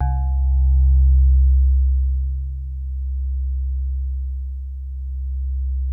TINE SOFT C1.wav